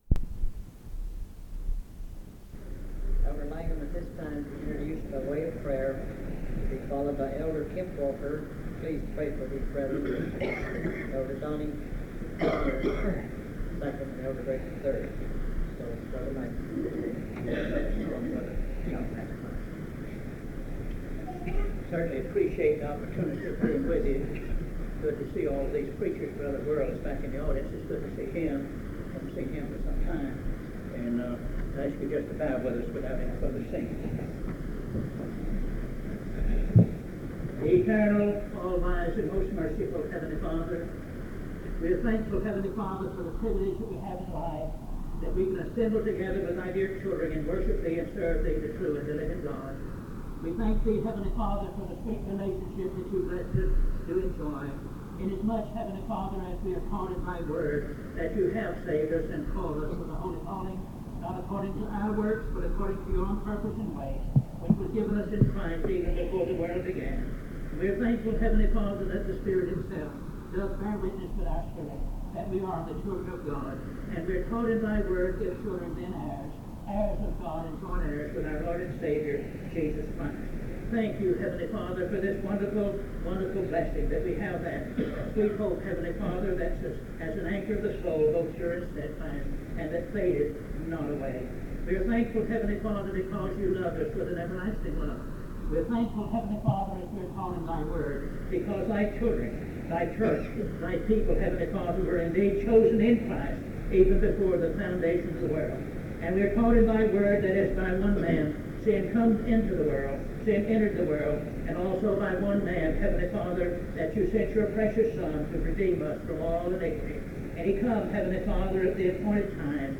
Recording of a service including sermons by three elders; The singing at the end may have come from a different occasion.